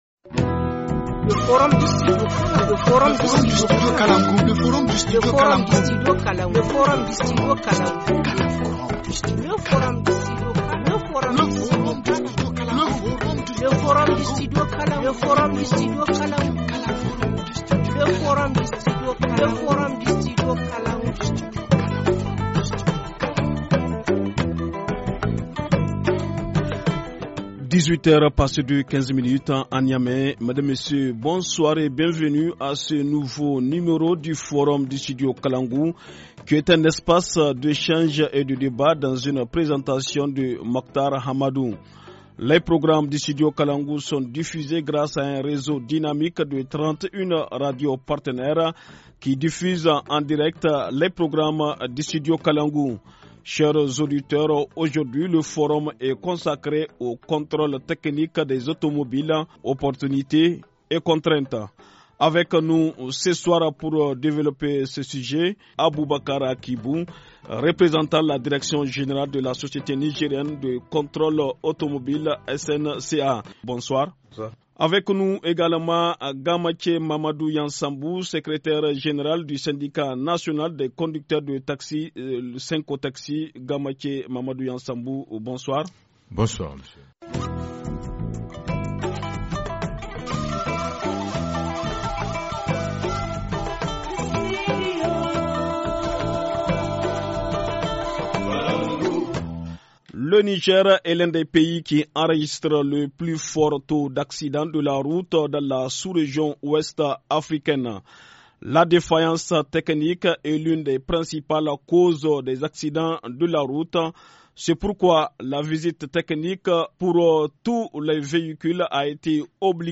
Forum 06/11/2017 : le contrôle technique des automobiles, opportunités et contraintes - Studio Kalangou - Au rythme du Niger